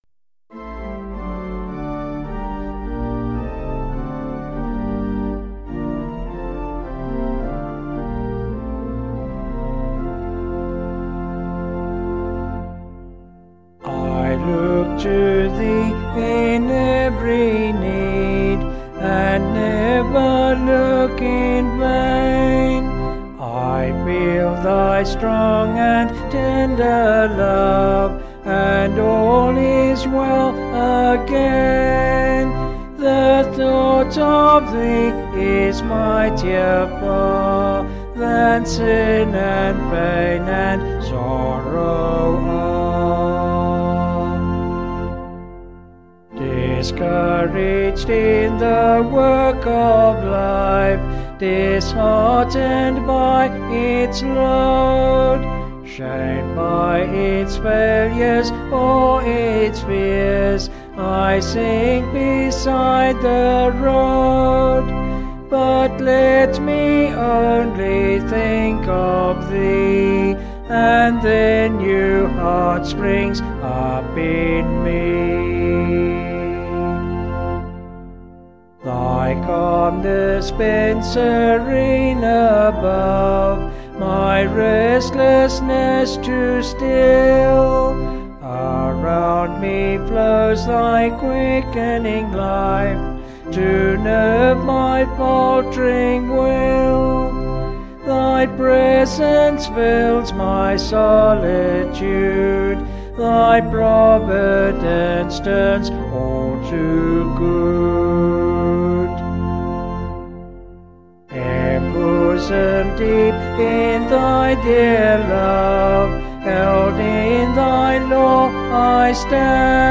Vocals and Organ   265kb Sung Lyrics